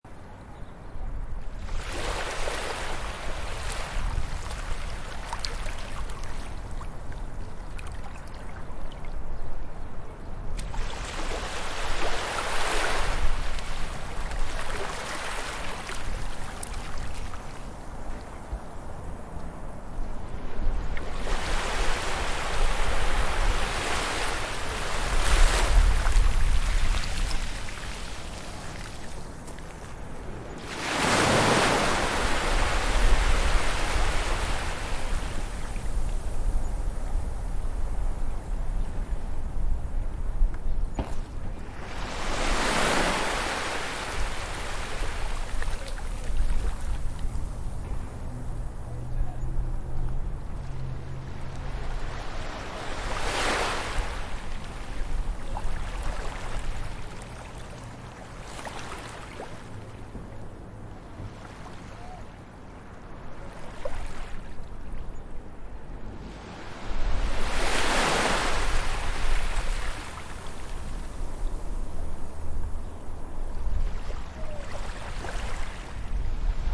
ptaki.mp3